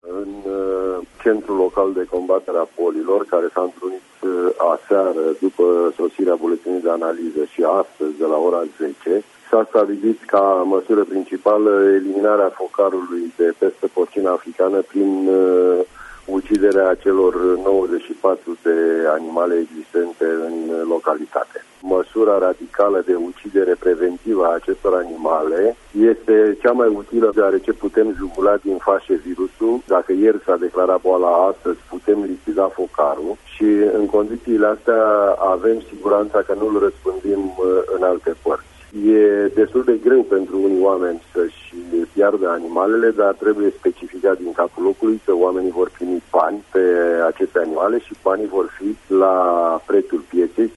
Andrei Butaru, director DSVSA Dolj: